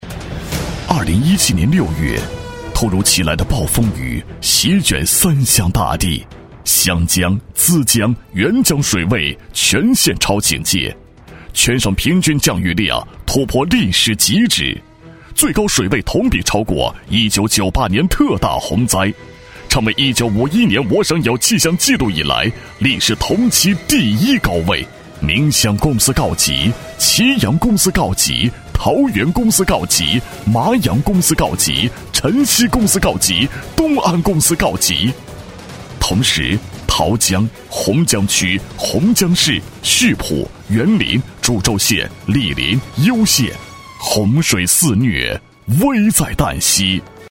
大气震撼 企业专题,人物专题,医疗专题,学校专题,产品解说,警示教育,规划总结配音
大气激情男中音，年轻时尚，厚重磁性。